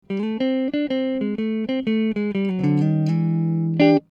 Акустический звук - Усилитель - "Regent30-Mod", гитарный динамик - "Celestion Greenback", 1 микрофон "Shure SM 57", сигнал с динамика - левый канал, сигнал с предварительного усилителя "Regent30-Mod" - правый канал (линия), микшер, звуковая карта компьютера.
Датчики : bridge - seymour duncan SH 1 (classic 59), neck - seymour duncan SH-2 jazz model
Датчик Bridge и distortion T324
На деле этот датчик имеет острый горб на 6 кгц, слабый выход и обрезанный низ, он очень похож по тембру на сингл.
С эффектом Distortion это становится особенно заметно.